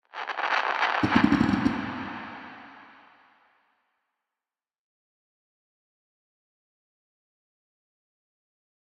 Minecraft Version Minecraft Version latest Latest Release | Latest Snapshot latest / assets / minecraft / sounds / ambient / nether / warped_forest / mood1.ogg Compare With Compare With Latest Release | Latest Snapshot